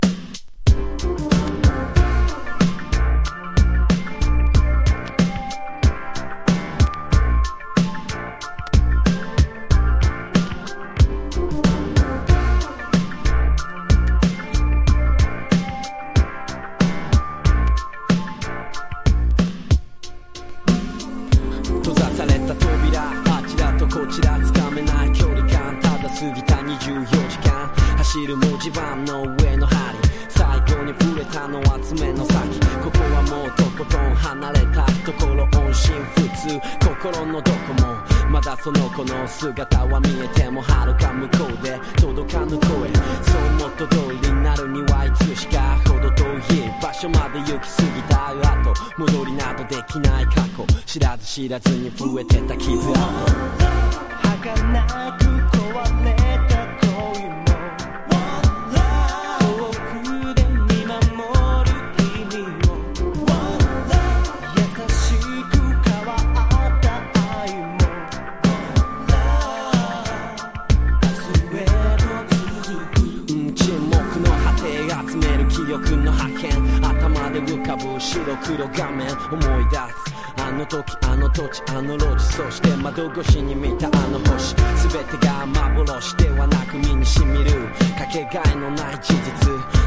• HIPHOP